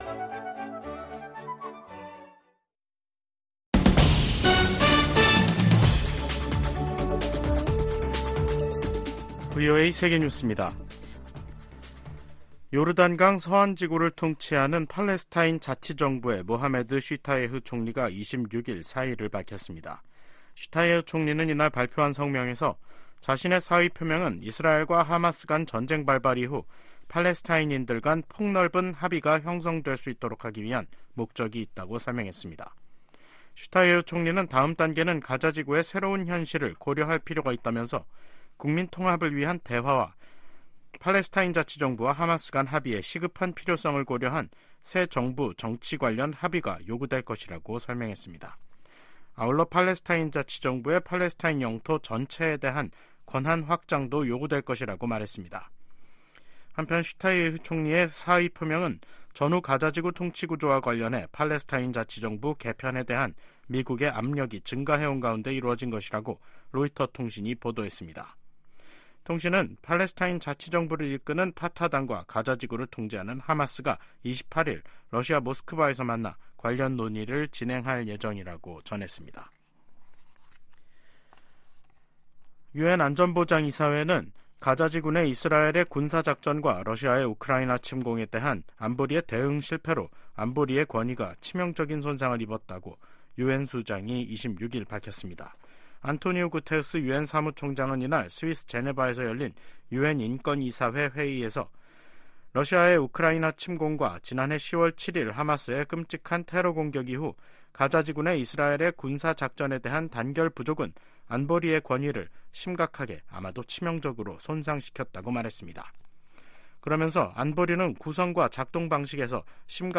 VOA 한국어 간판 뉴스 프로그램 '뉴스 투데이', 2024년 2월 26일 3부 방송입니다. 주요7개국(G7) 정상들이 우크라이나 전쟁 2주년을 맞아 북한-러시아 탄도미사일 거래를 규탄했습니다. 미국 정부의 대규모 러시아 제재에 대북 무기 수출에 관여한 러시아 물류 회사와 한국 기업 한 곳이 제재 명단에 올랐습니다. 미국과 중국의 북핵 대표가 최근 화상 회담을 갖고 한반도 문제를 논의했습니다.